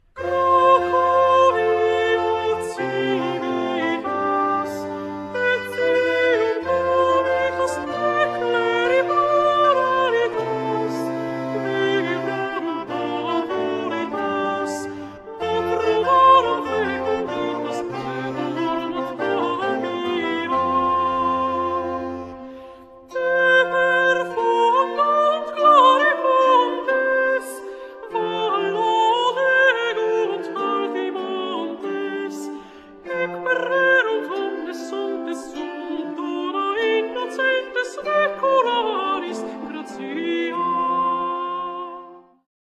kontratenor